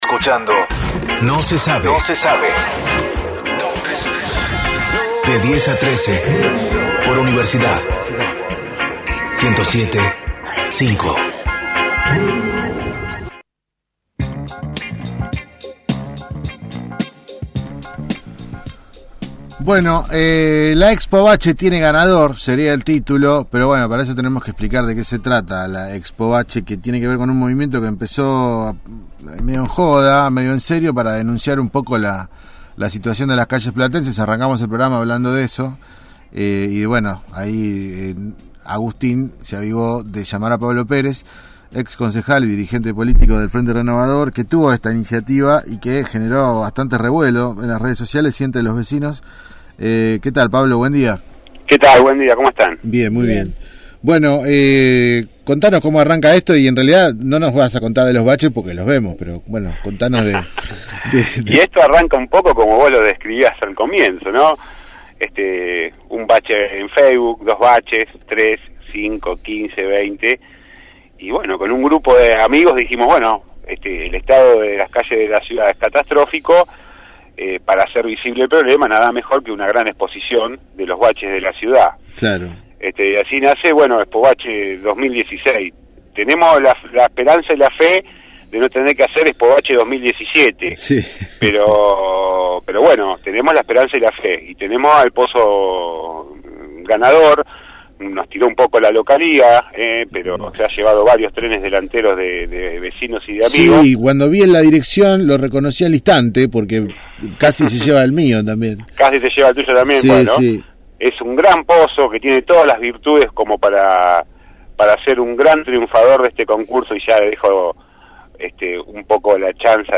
Pablo Pérez, ex concejal y organizador de «Expo bache», dialogó con el equipo de «No se sabe» sobre la particular muestra fotográfica de los pozos de la ciudad, creada con el aporte de los vecinos para reclamar arreglos en los pavimentos de las distintas zonas.